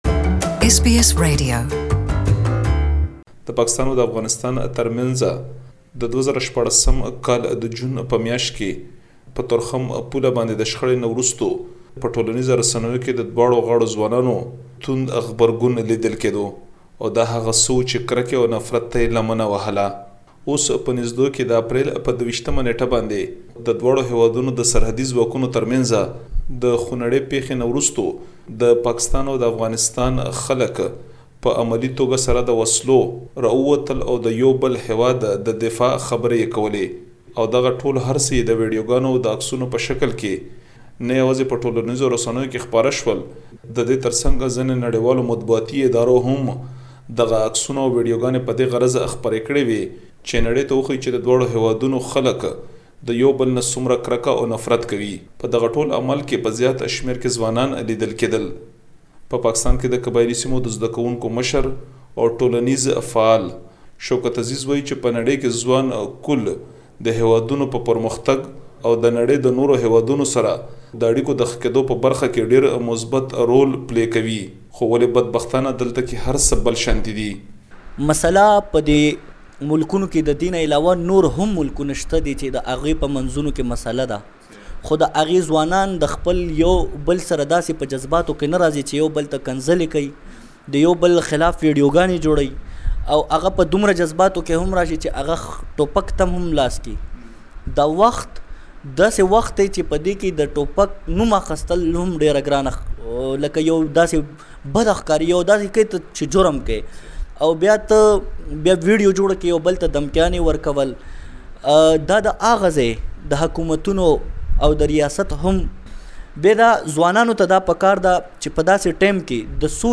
has a report